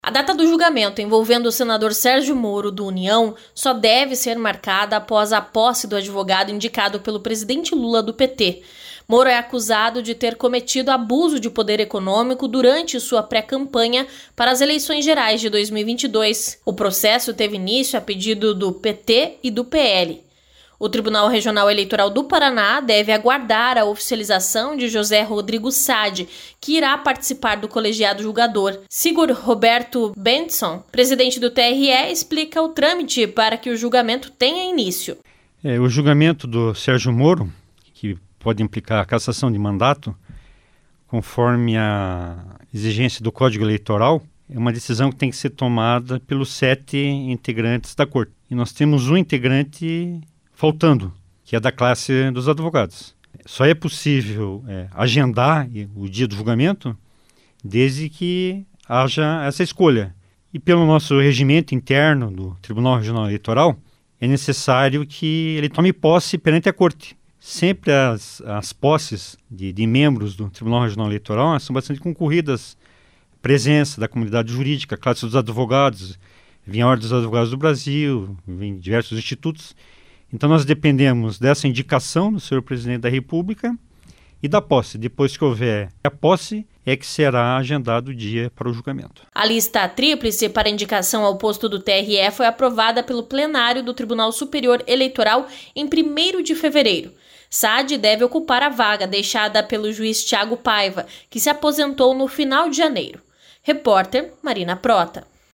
Sigurd Roberto Bengtsson, presidente do TRE, explica o trâmite para que o julgamento tenha início.